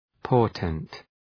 Προφορά
{‘pɔ:rtent}